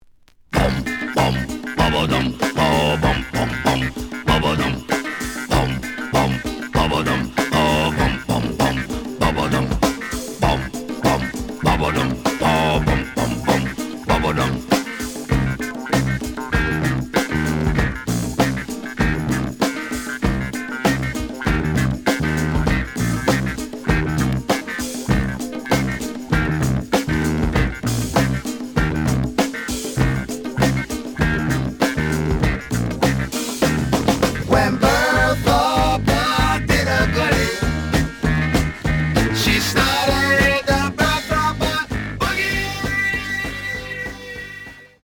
The audio sample is recorded from the actual item.
●Genre: Funk, 70's Funk
B side plays good.)